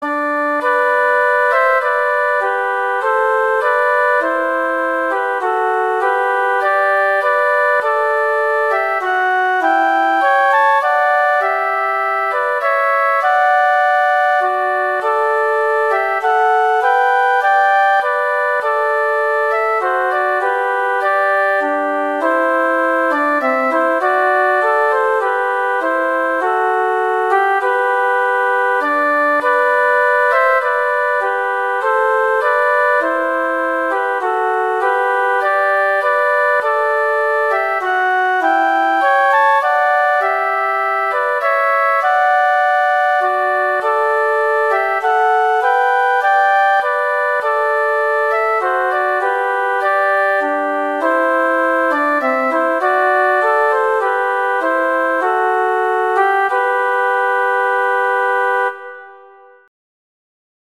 Instrumentation: two flutes